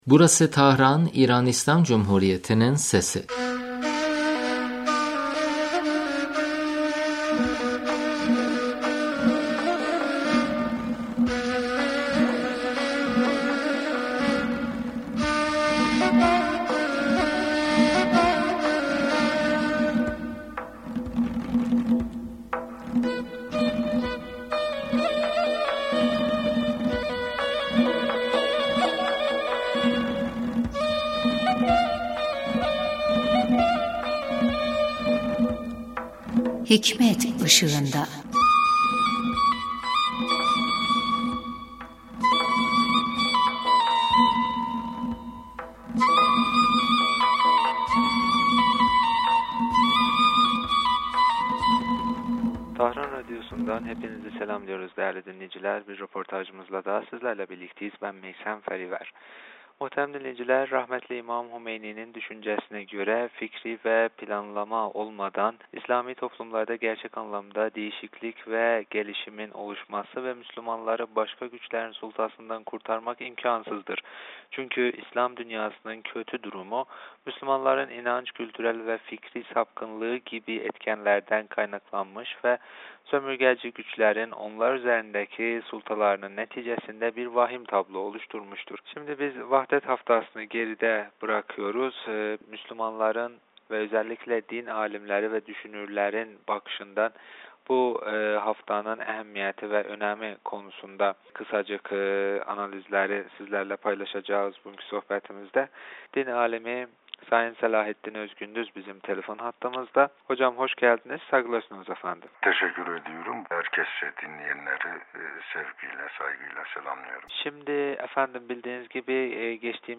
radyomuza verdiği demecinde vahdet haftası münasebeti ve islam dünyasının birlikteliği hakkında görüşlerini bizimle paylaştı.